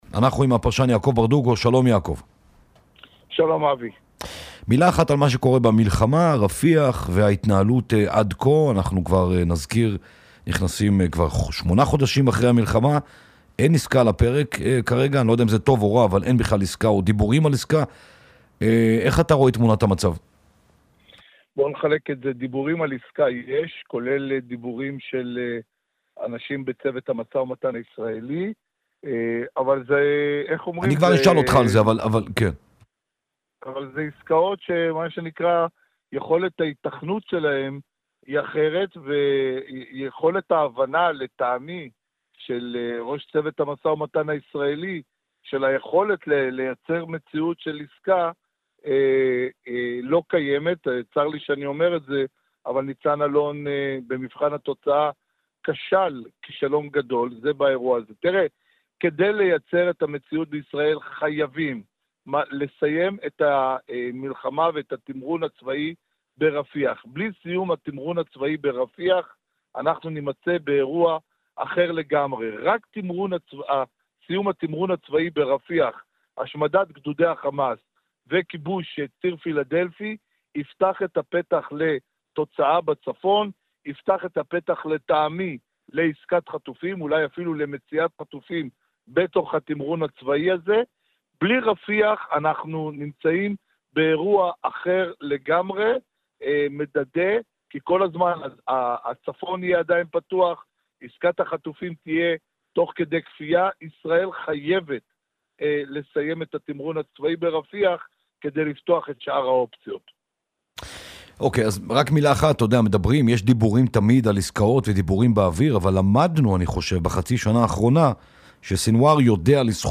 הפרשן יעקב ברדוגו, שוחח הערב (שלישי)